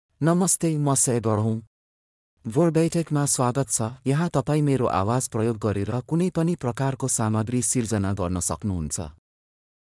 Sagar — Male Nepali AI voice
Sagar is a male AI voice for Nepali (Nepal).
Voice sample
Listen to Sagar's male Nepali voice.
Male
Sagar delivers clear pronunciation with authentic Nepal Nepali intonation, making your content sound professionally produced.